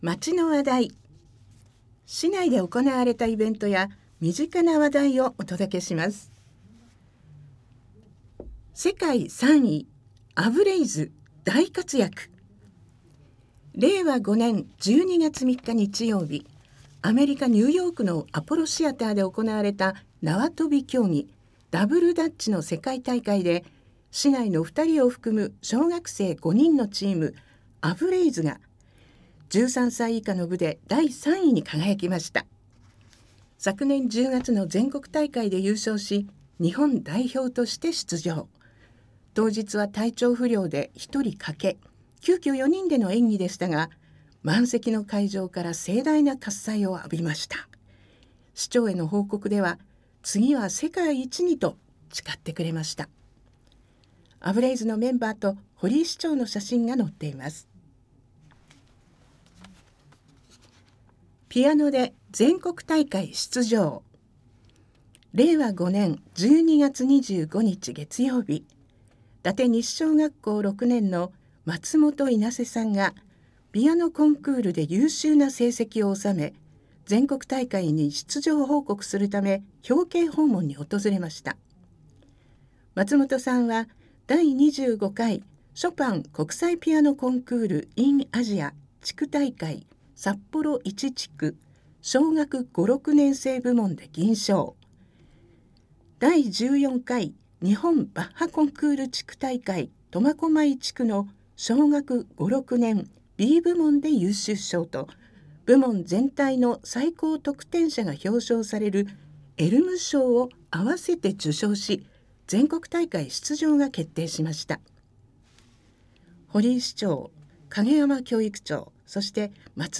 ■朗読ボランティア「やまびこ」が音訳しています